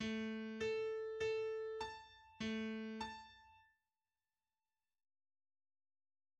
A lilypond a zongora hangszínét használja alaphelyzetben, de ez is változtatható.